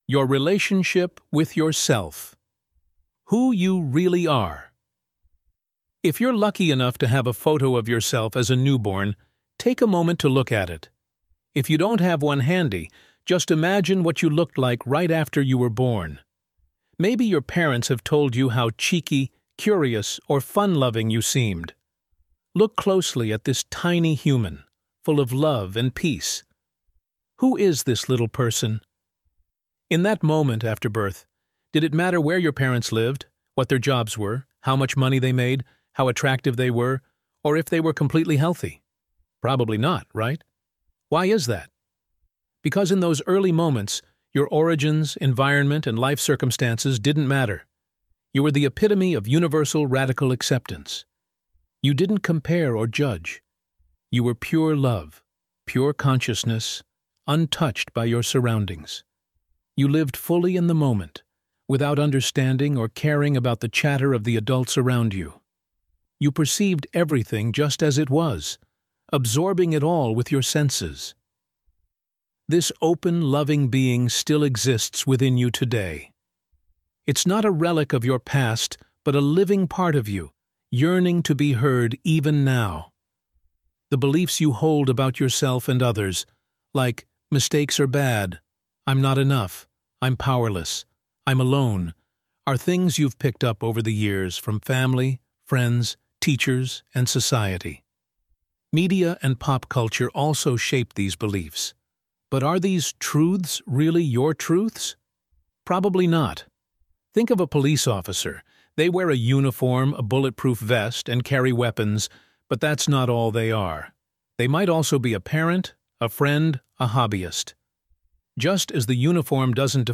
This empowering audiobook includes 16 insight sheets to deepen your journey.